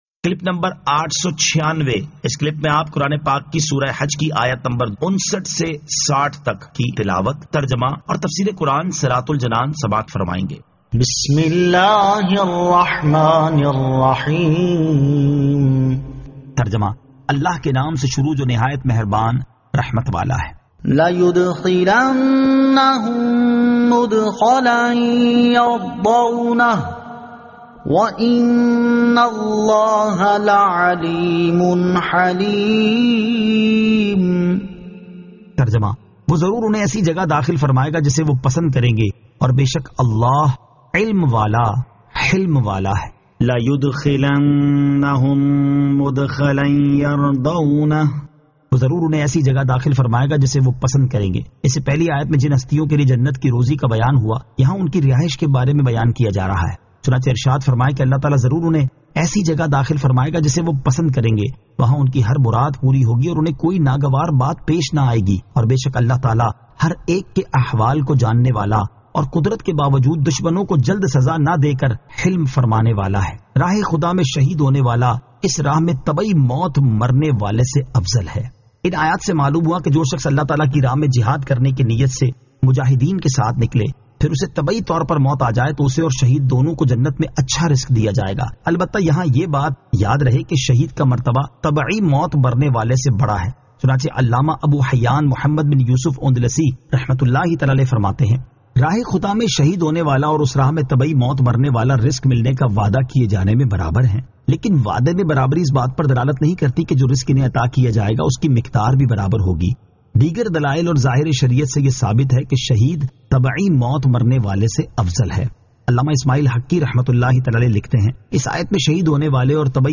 Surah Al-Hajj 59 To 60 Tilawat , Tarjama , Tafseer